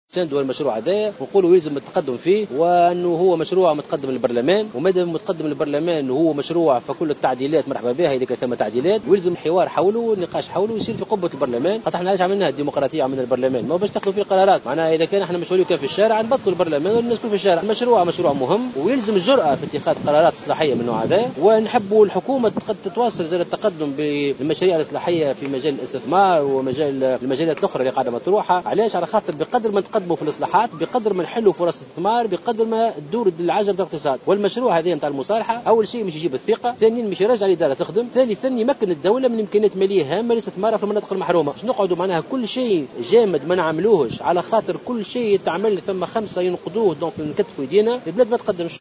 أكد القيادي في حركة نداء تونس محسن مرزوق على هامش اختتام فعاليات الندوة الاقليمية لإطارات الحركة بولايات سوسة و المنستير والمهدية والقيروان وسيدي بوزيد ضرورة مساندة مشروع قانون المصالحة والعمل على التقدم فيه .